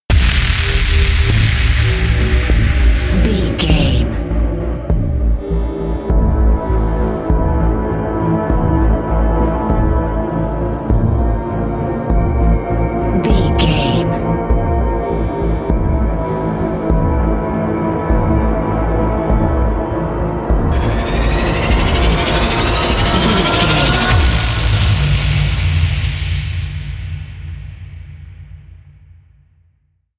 Survival horror
Aeolian/Minor
synthesiser
percussion
ominous
suspense
haunting
creepy